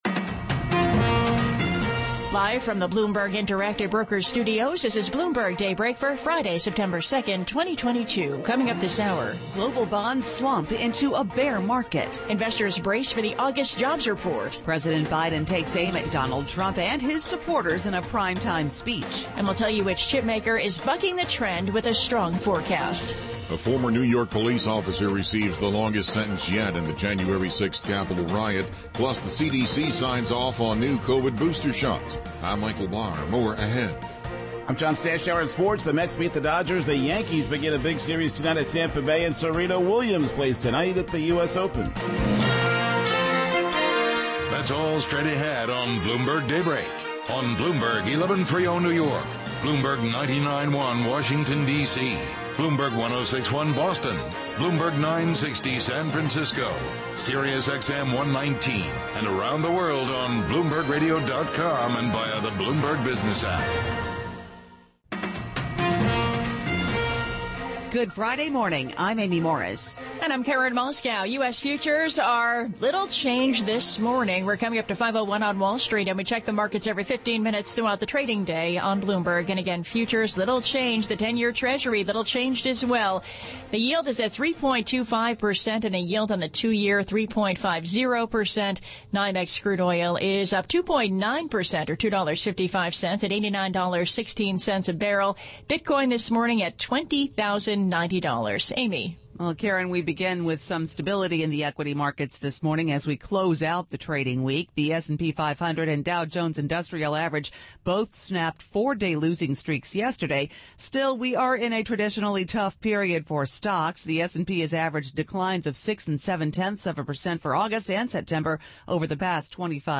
Bloomberg Daybreak: September 2, 2022 - Hour 1 (Radio) Bloomberg Daybreak: US Edition podcast